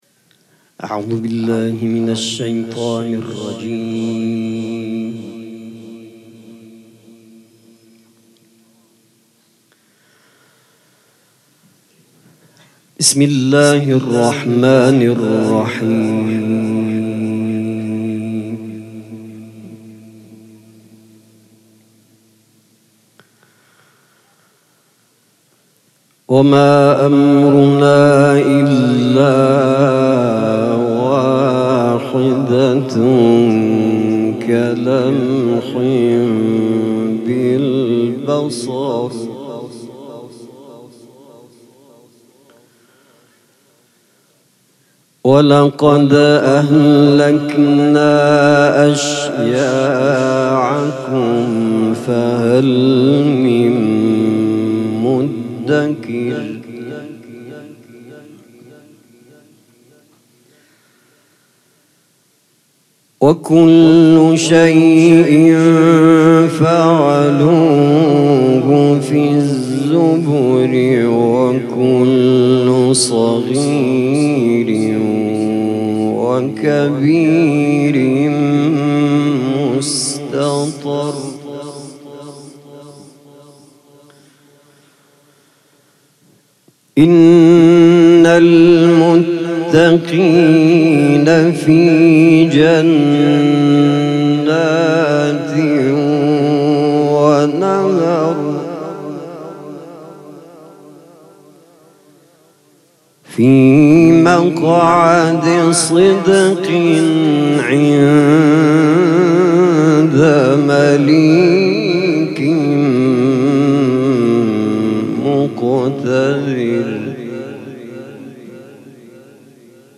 سبک اثــر قرائت قرآن